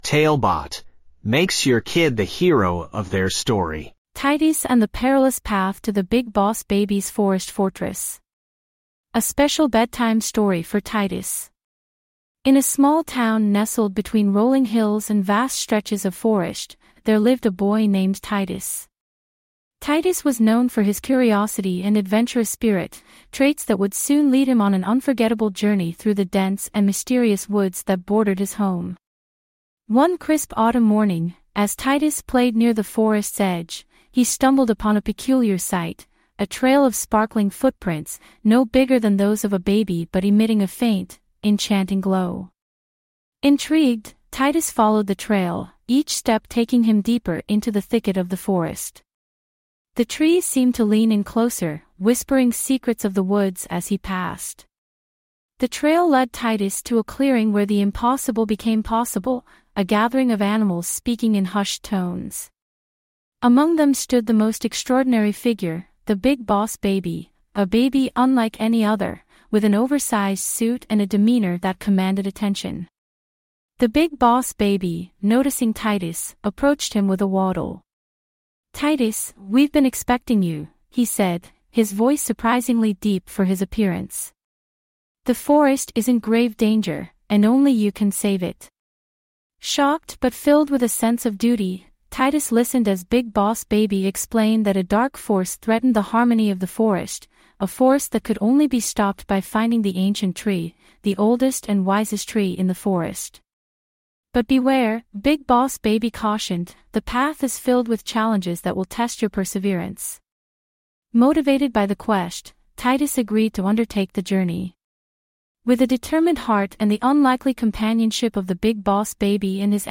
5 minute bedtime stories.